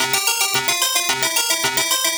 Index of /musicradar/8-bit-bonanza-samples/FM Arp Loops
CS_FMArp C_110-C.wav